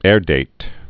(ârdāt)